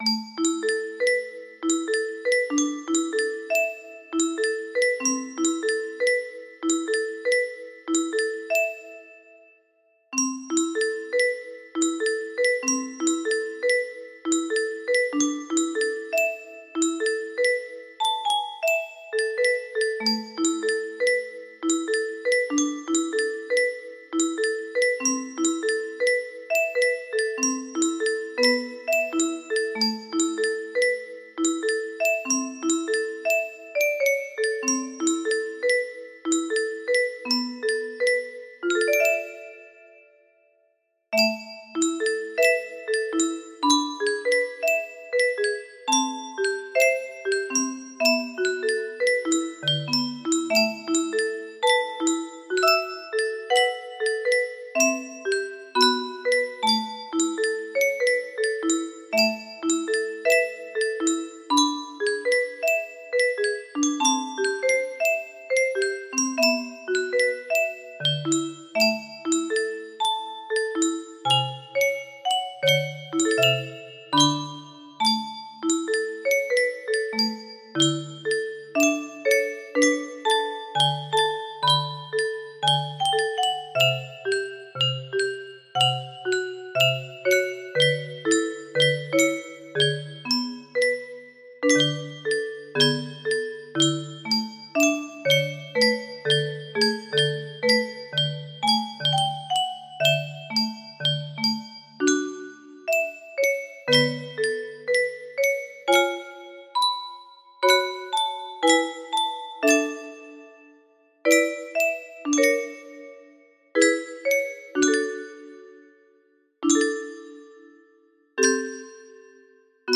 BPM 115